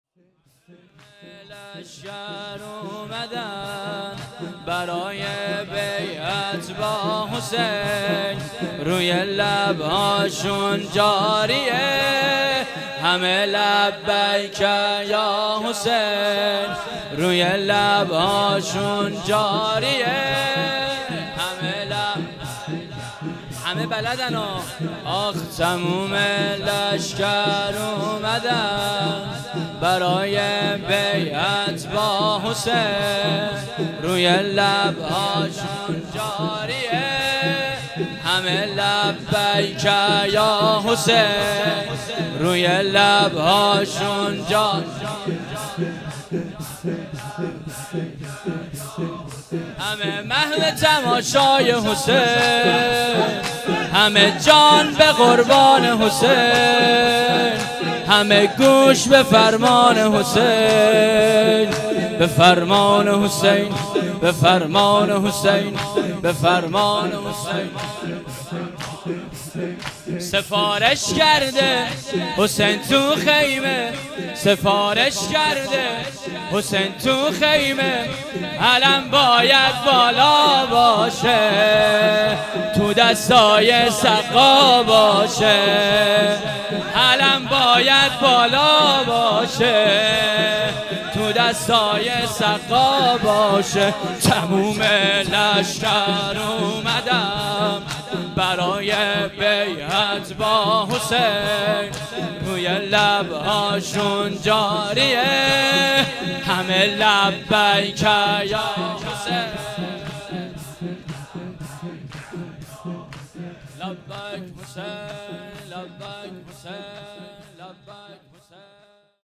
شب دهم محرم1398